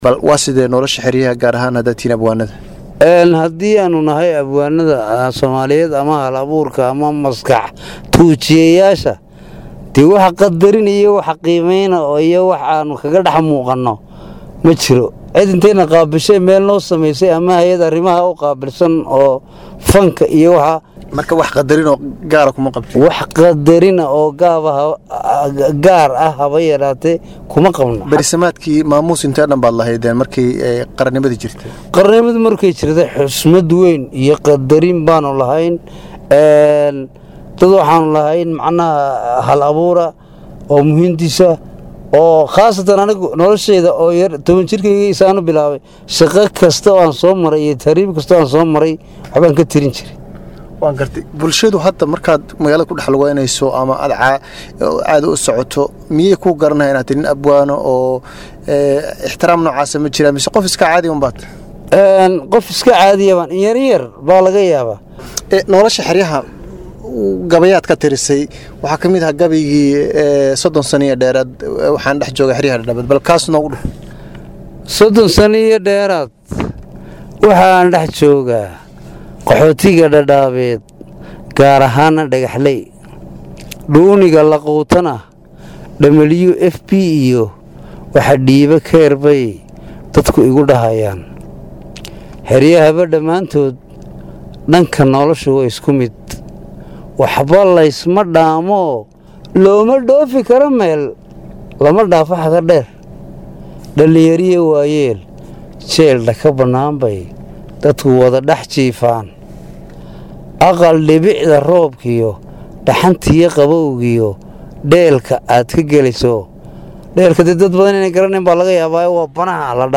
DHAGEYSO:Wareysi: Abwaan duruufo ku wajahaya Dadaab
Wareysi-Abwaan-Dadaab.mp3